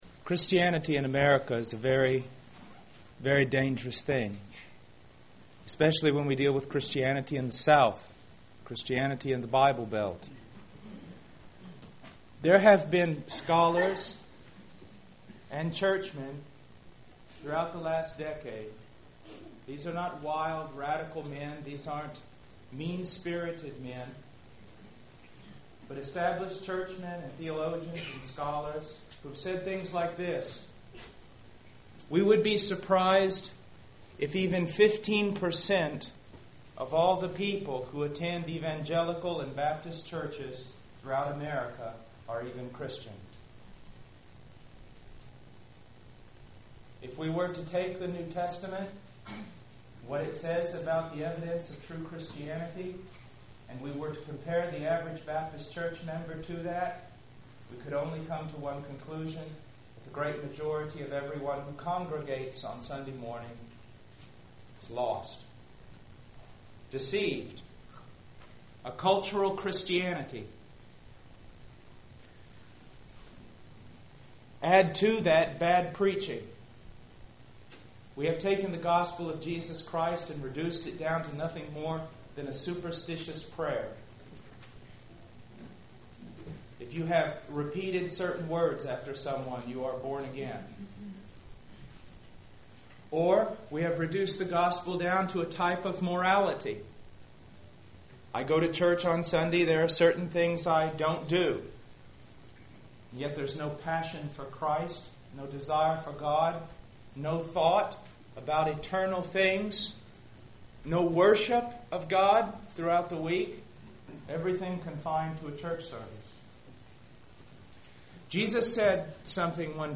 In this sermon, the speaker emphasizes the importance of examining oneself to determine if Jesus Christ truly dwells within. He compares the obvious signs of a demon-possessed person to the less evident signs of a true Christian. The speaker challenges the audience to reflect on their own lives and consider whether they are genuinely seeking to know God and follow His will.